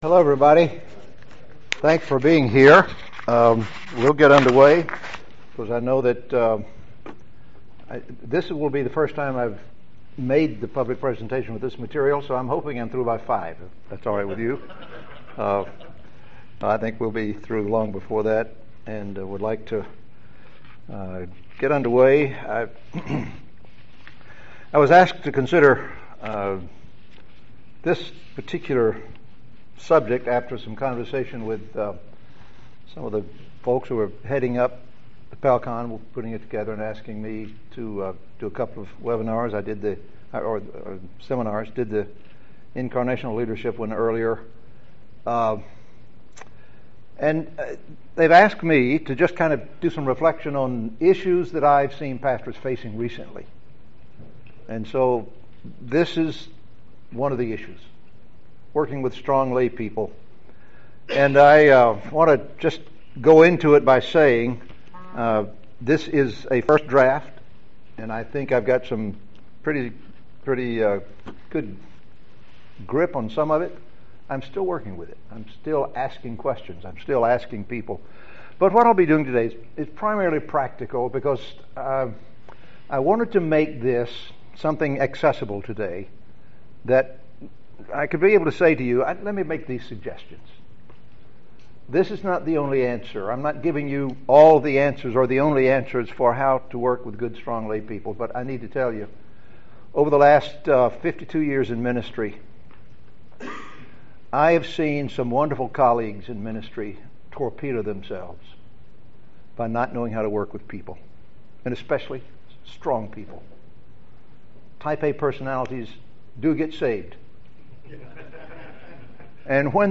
God has gifted many congregations with strong lay leaders, but pastors and staff don’t always understand how to best equip and use such lay leaders. This workshop, sponsored by the NTS Center for Pastoral Leadership, will identify patterns of lay leadership in churches of differing sizes and cultures and offer best practices in utilizing the gifts of strong lay leaders.